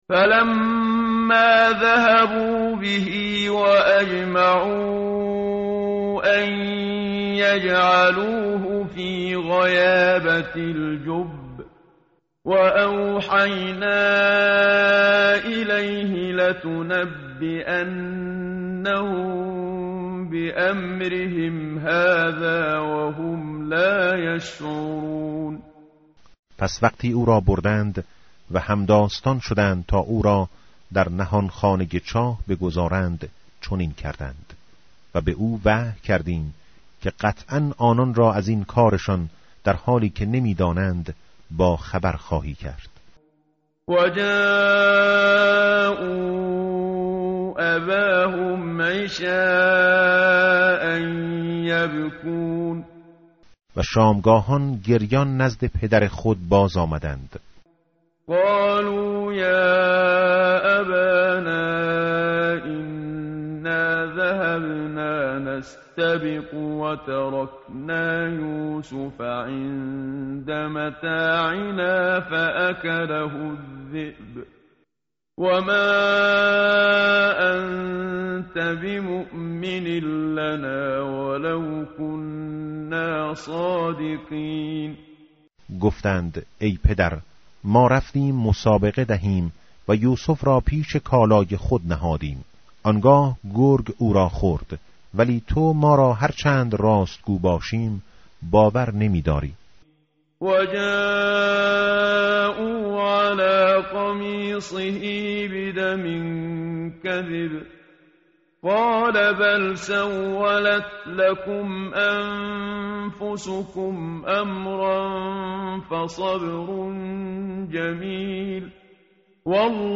tartil_menshavi va tarjome_Page_237.mp3